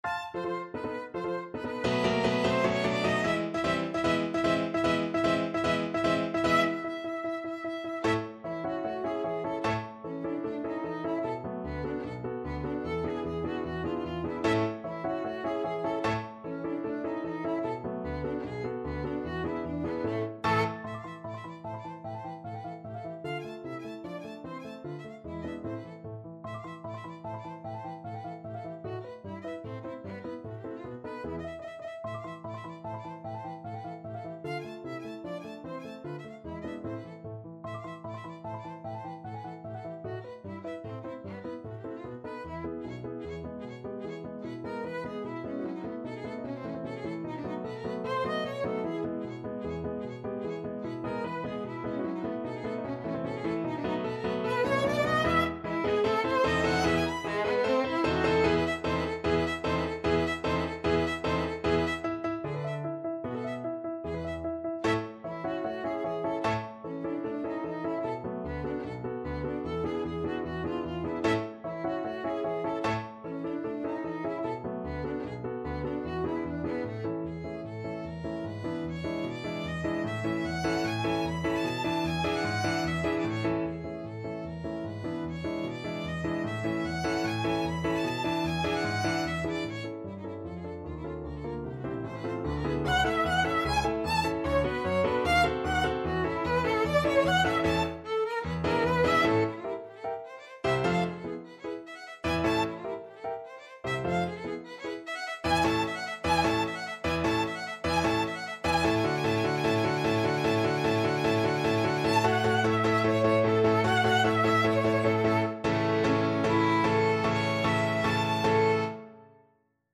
Violin
A major (Sounding Pitch) (View more A major Music for Violin )
2/4 (View more 2/4 Music)
Allegro vivacissimo ~ = 150 (View more music marked Allegro)
Classical (View more Classical Violin Music)
galop_dance_of_the_hours_VLN.mp3